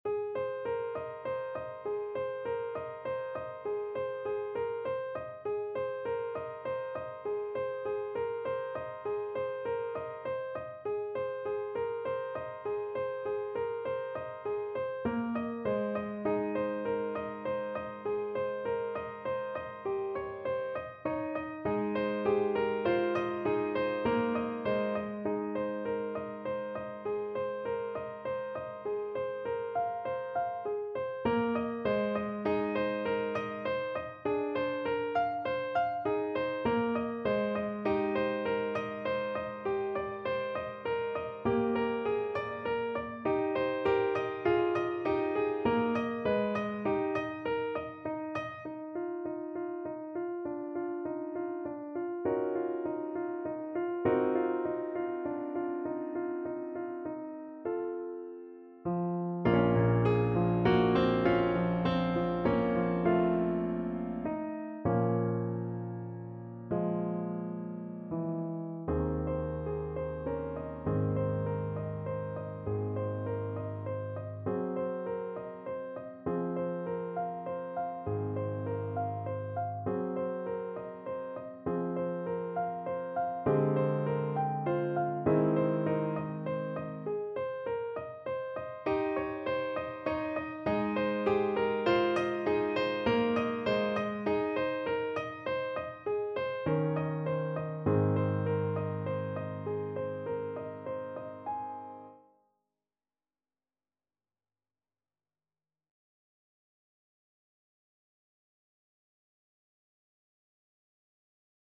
Play (or use space bar on your keyboard) Pause Music Playalong - Piano Accompaniment Playalong Band Accompaniment not yet available reset tempo print settings full screen
~ = 120 Allegretto
Ab major (Sounding Pitch) Bb major (Trumpet in Bb) (View more Ab major Music for Trumpet )
Classical (View more Classical Trumpet Music)